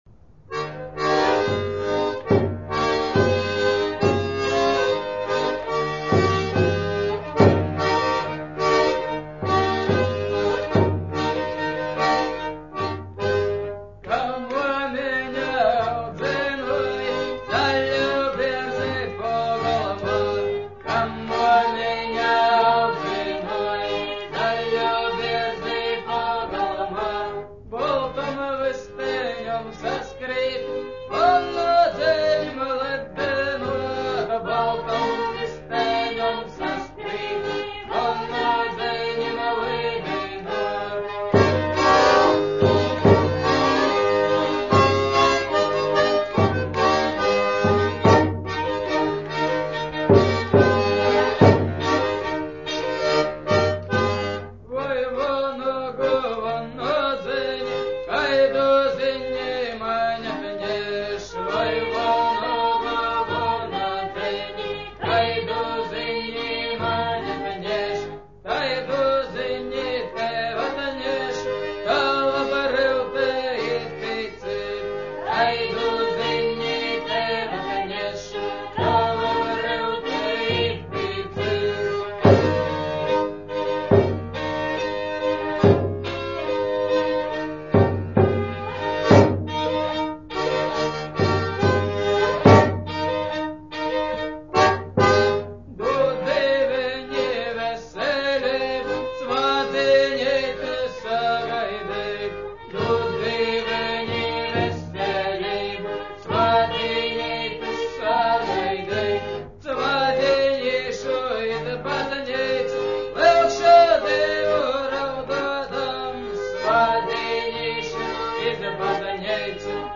Latvian folk songs